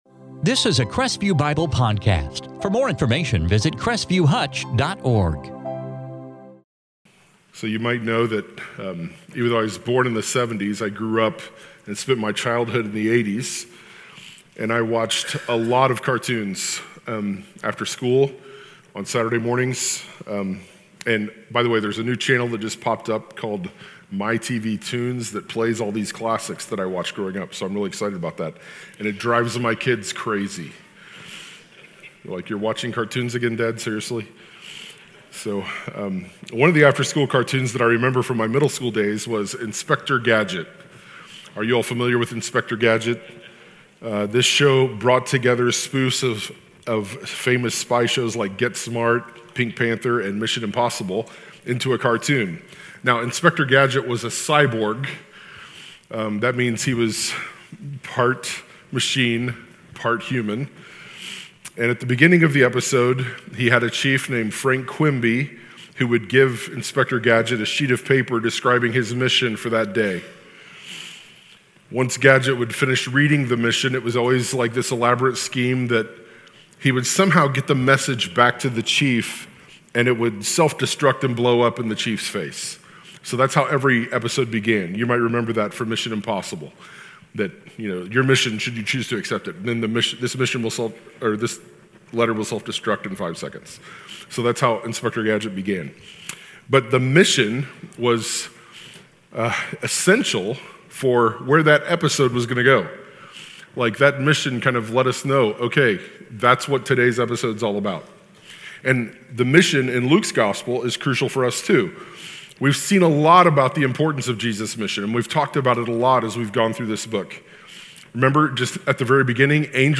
2024 Gospel of Luke Luke 18:31-43 In this sermon from Luke 18:31-19:27